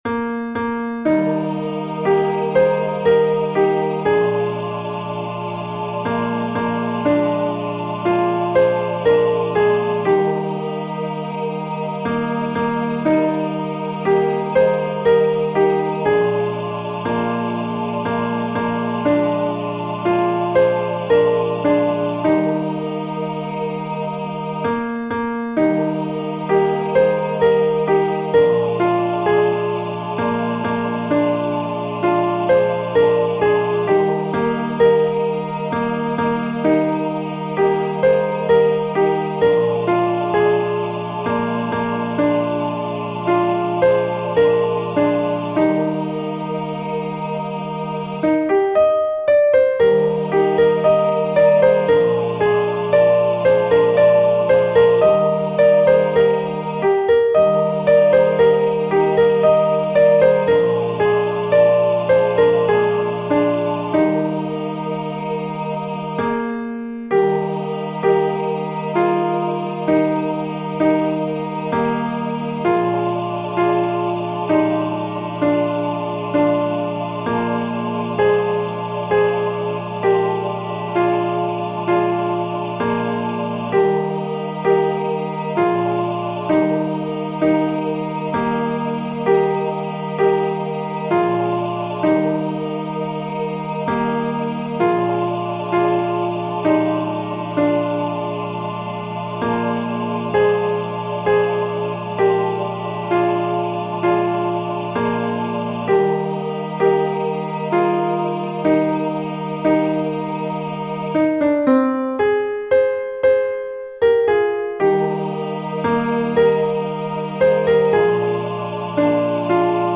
Demos zum Herunterladen